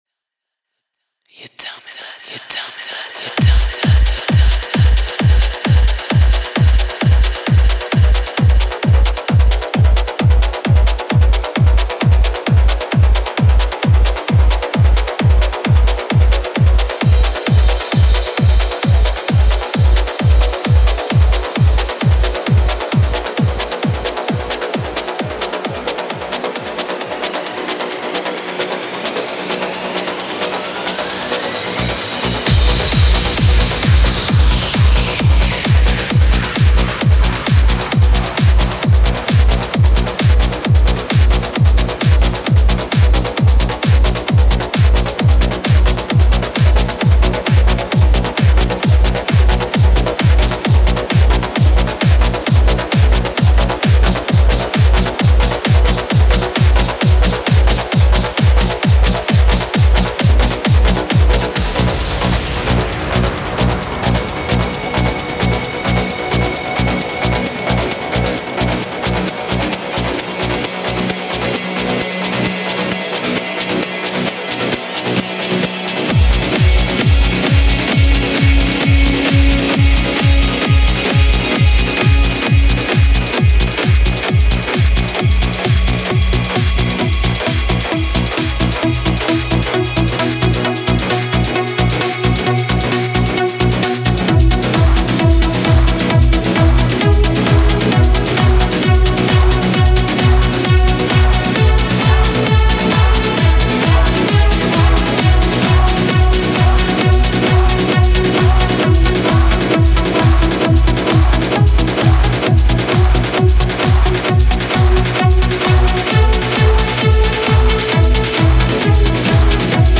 Genre: trance, edm, electronic.